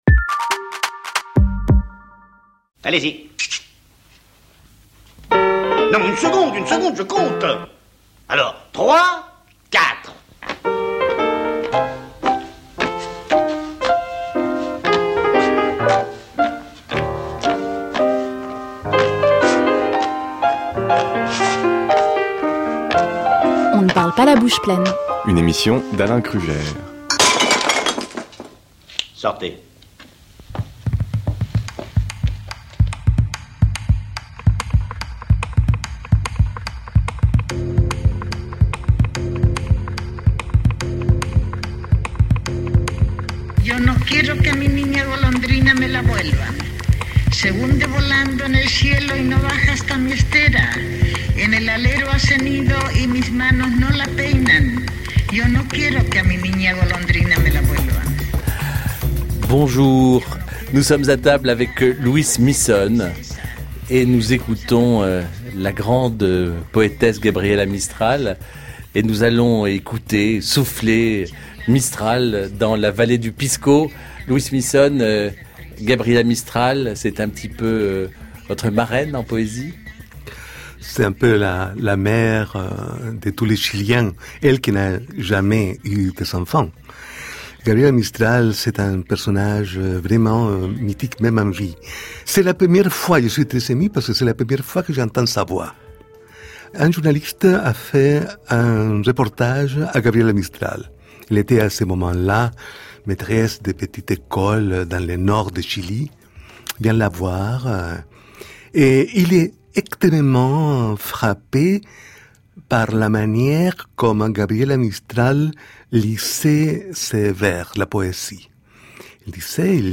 POÈTE ET ROMANCIER CHILIEN